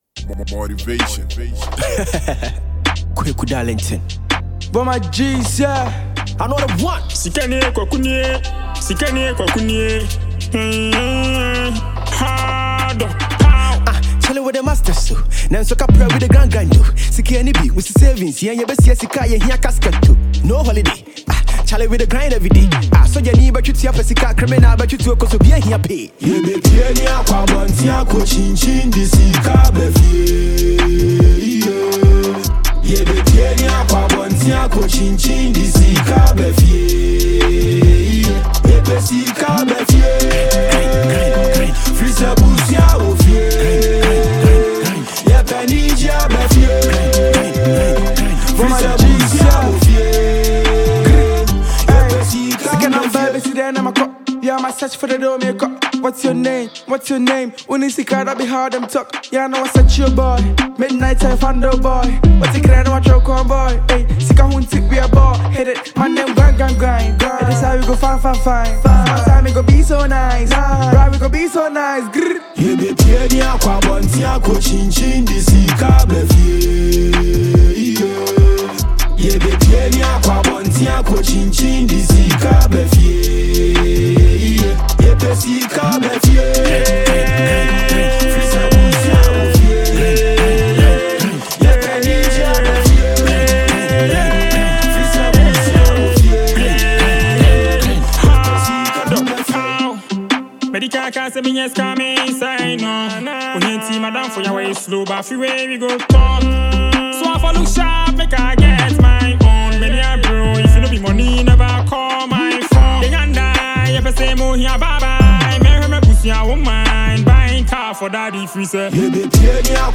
Fast-rising Ghanaian rapper